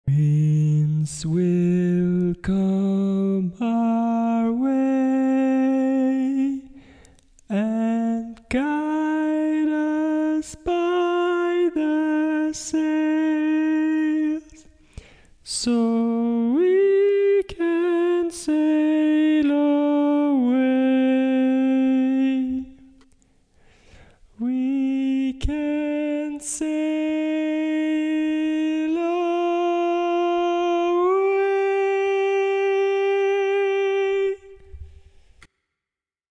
Key written in: E♭ Major
Type: Barbershop
Comments: Original tag, ballad-style barbershop
Each recording below is single part only.